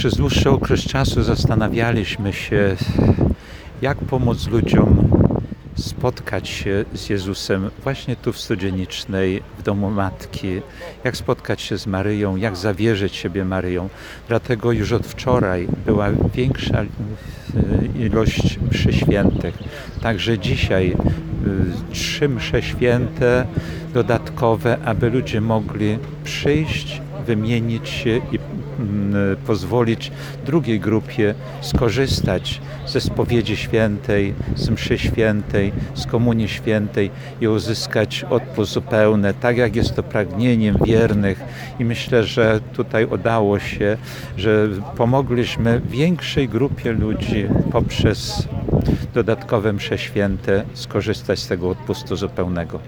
Ksiądz biskup przyznaje, że duchowni przez dłuższy czas zastanawiali się, jak zapewnić wiernym bezpieczeństwo, podczas wspólnej modlitwy. Stąd decyzja o większej liczbie mszy świętych.